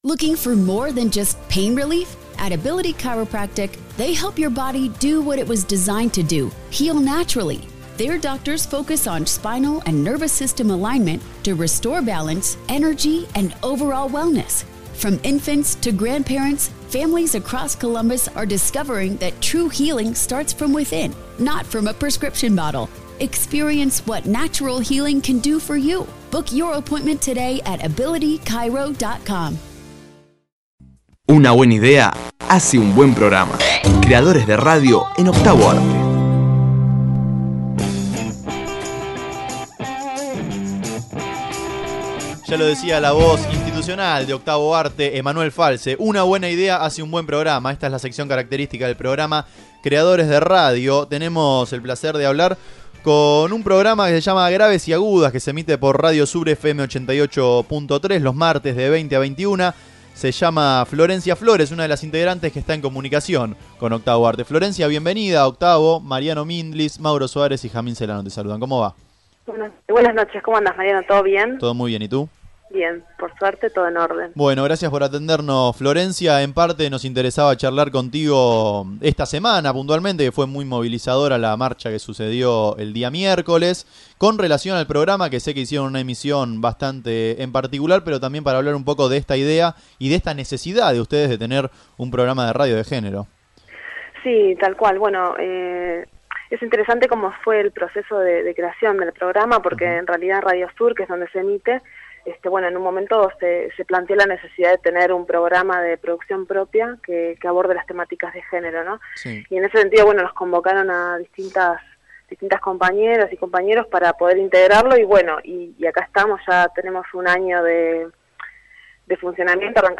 Entrevista Octavo arte